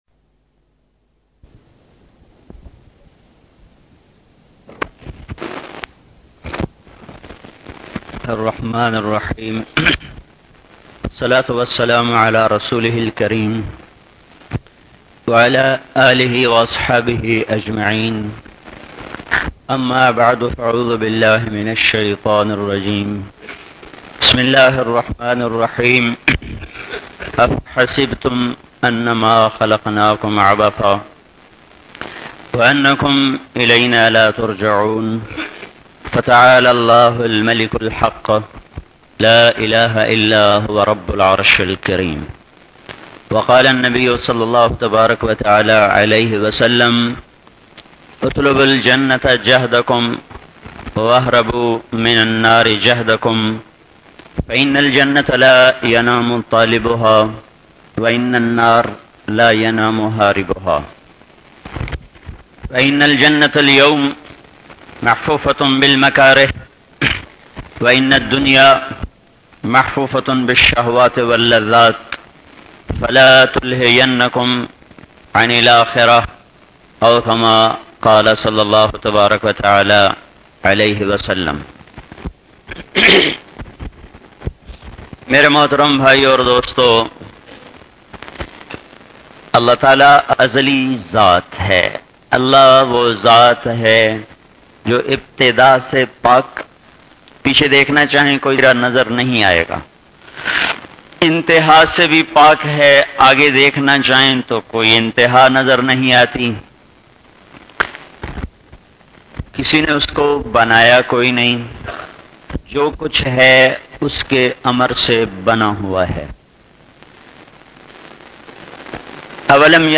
JANNAT KI CHABI byan by Moulana TAariq Jameel